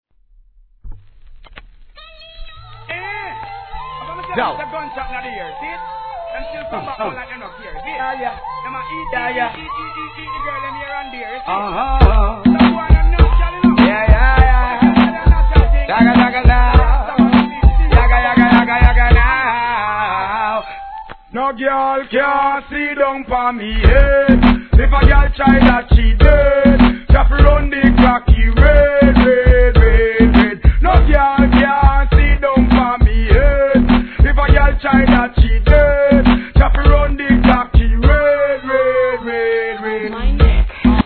REGGAE
HIP HOPの大ヒット・トラックを用いたエスニックなHIT RIDDIM!!!